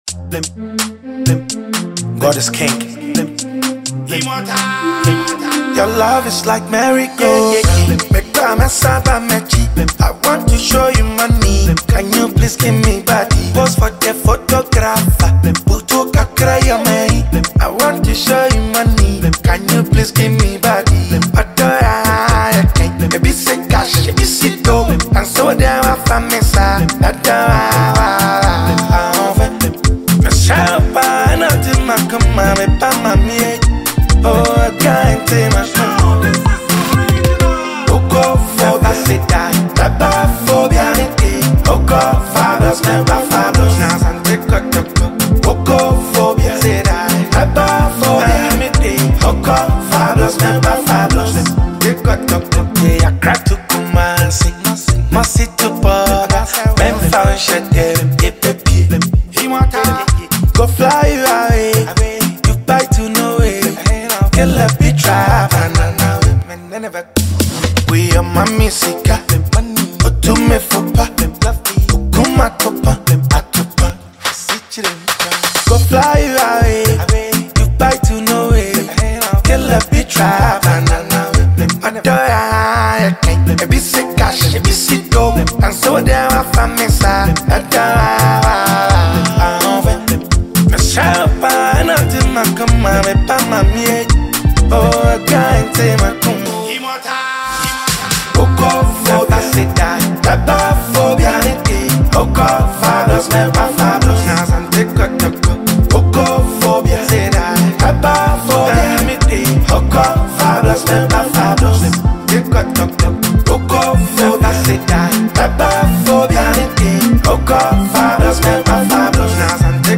Highlife singer, rapper and award-wining Ghanaian musician